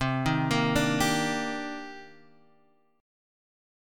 Cm9 chord